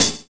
Closed Hats
Wu-RZA-Hat 42.wav